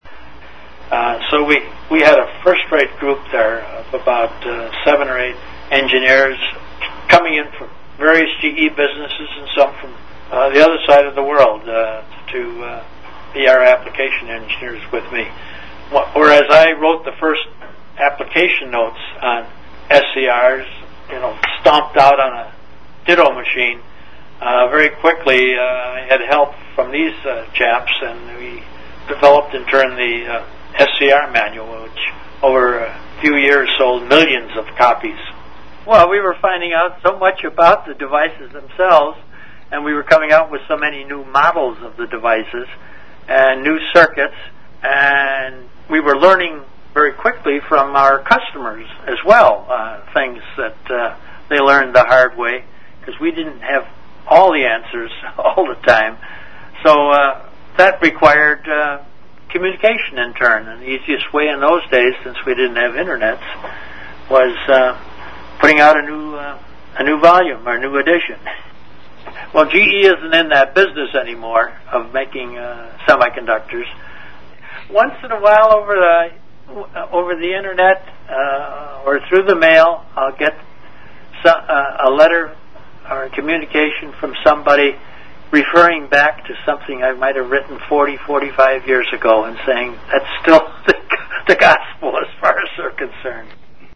from a 2005 Interview with